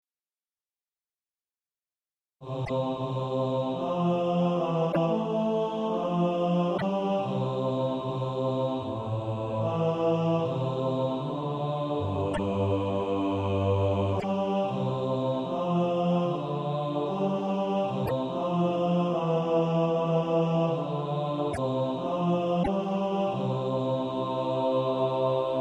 Bass Track.
(SATB) Author
Practice then with the Chord quietly in the background.